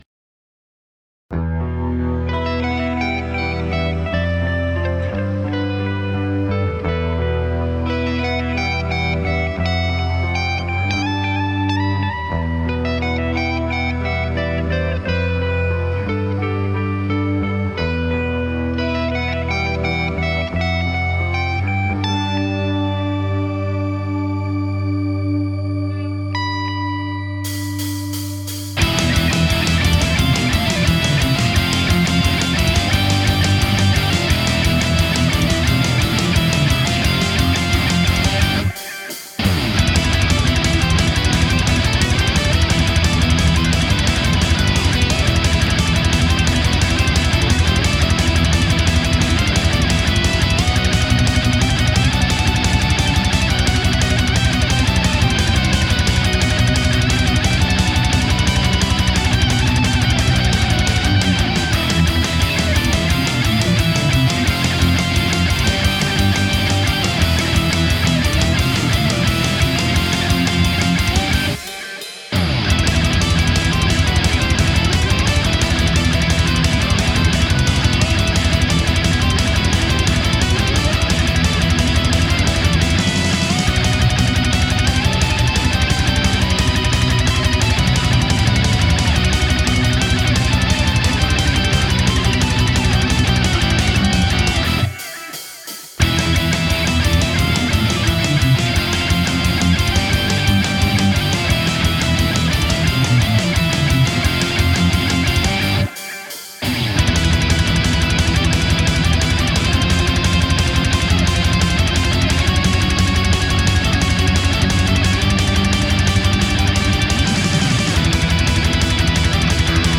Wie der Thread-Titel schon sagt, geht es mir bei folgendem, verhältnismäßig einfsllslosen Geballer vor allem um den Sound der Gitarren. Das Intro mal ausgenommen, das sind Presets vom Hotone Ampero :) Es ist die erste Aufnahme mit dem Teil.